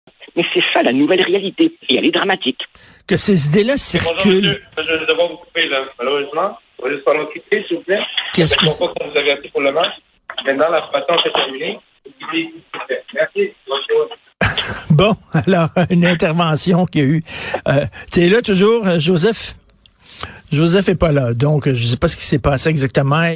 Joseph Facal qui se fait kicker out durant une entrevue parce qu’il ne porte pas son masque!